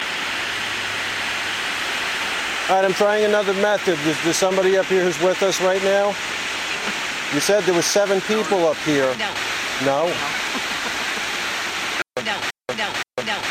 So I started to ask a question and asked about the "7" and got a crystal clear "NO!".